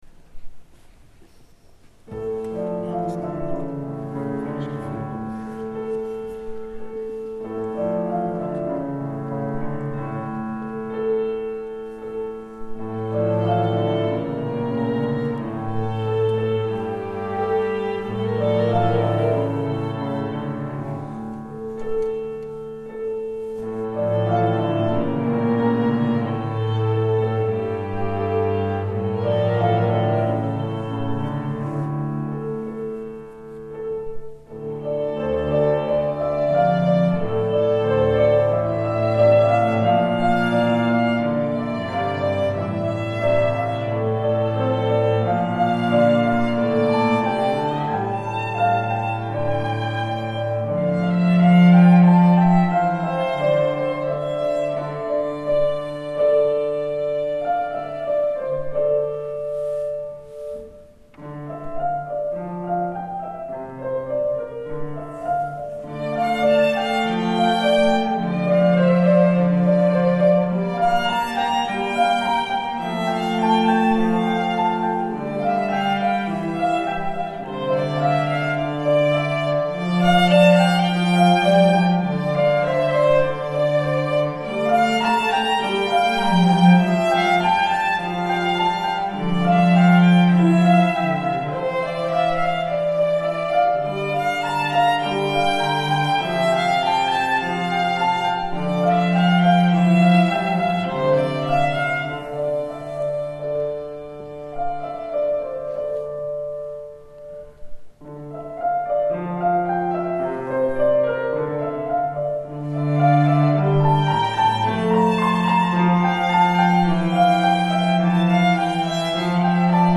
Piano trio – Trio Tableaux
A piano trio piece